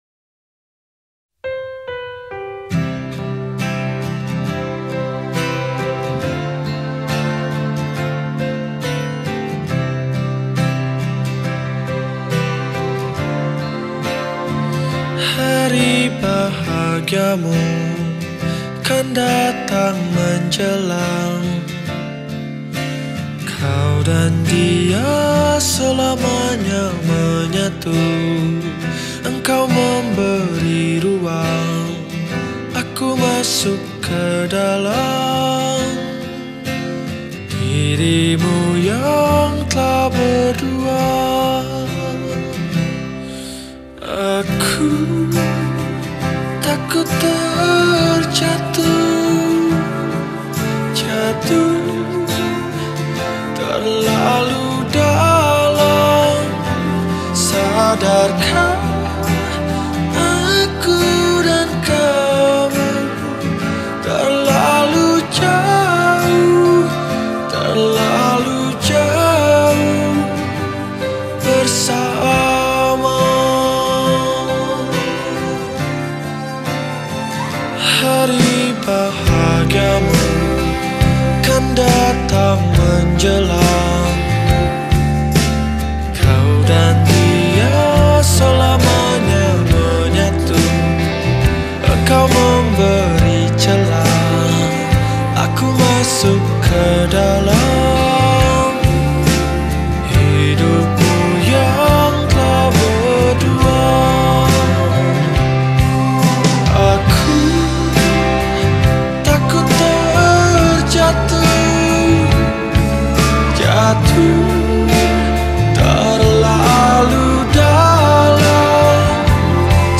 grup musik pop Indonesia